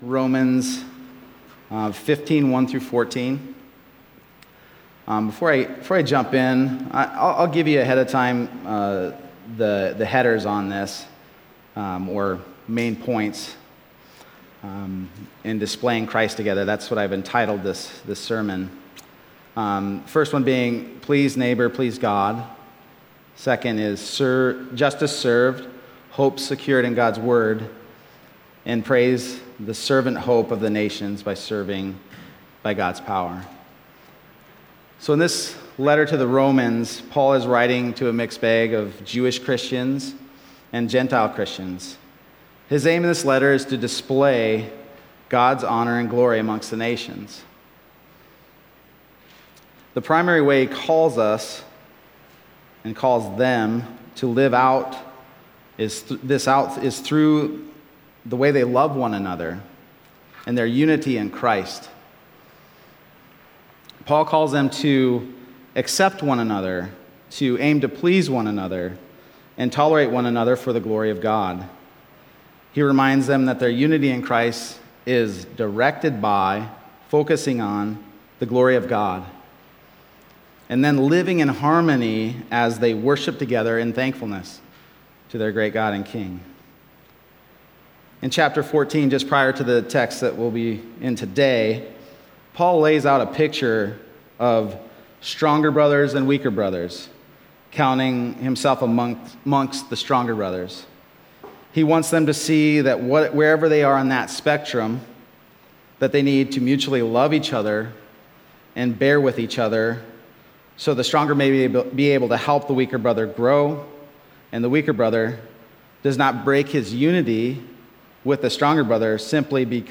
Other Passage: Romans 15:1-14 Service Type: Sunday Morning Romans 15:1-14 « Gospel